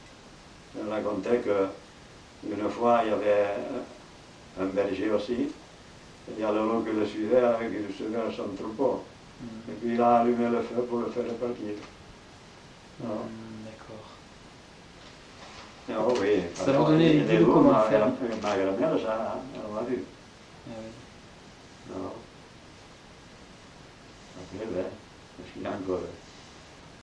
Aire culturelle : Couserans
Genre : conte-légende-récit
Effectif : 1
Type de voix : voix d'homme
Production du son : parlé
Classification : récit de peur